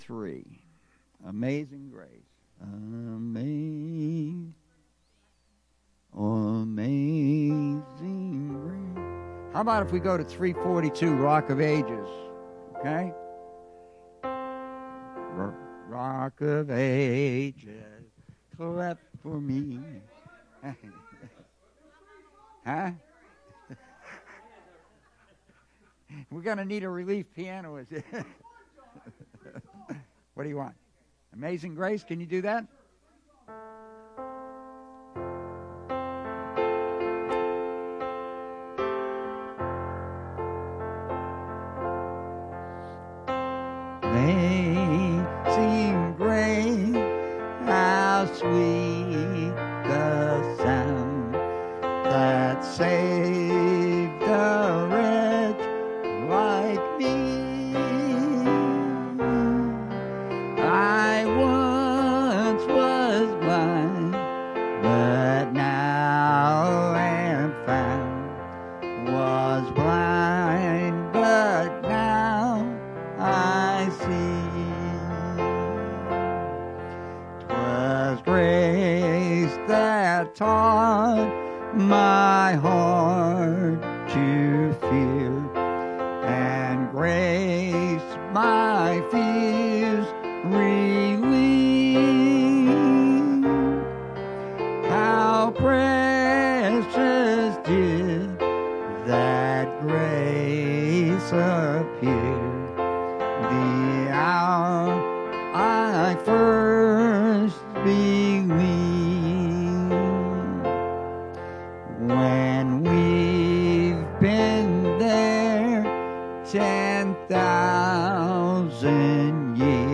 Bible Text: Revelation 12:1-6 | Preacher: